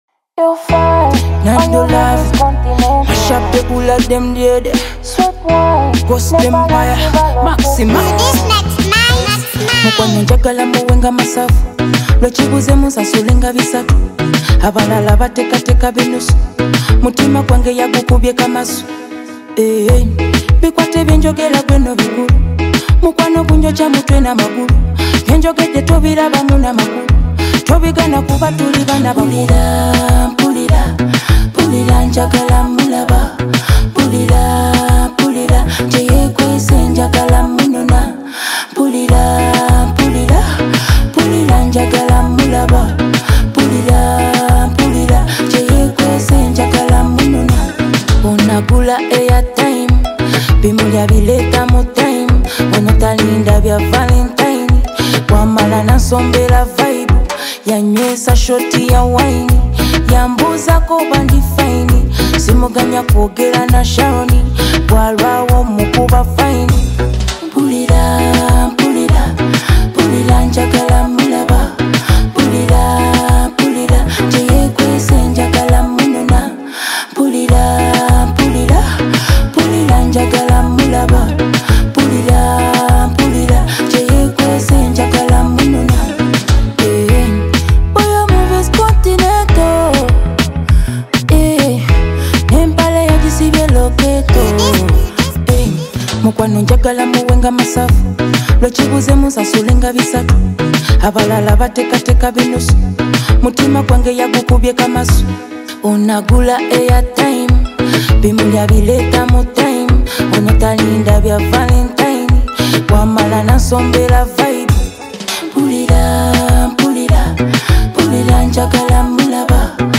a slow and emotional song
This one is calm, soulful, and straight from the heart.
is just a clean, heartfelt sound.